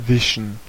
Ääntäminen
Synonyymit stick encounter brushwood paint brush Ääntäminen US Tuntematon aksentti: IPA : /bɹʌʃ/ IPA : /brʌʃ/ Haettu sana löytyi näillä lähdekielillä: englanti Käännös Ääninäyte Substantiivit 1.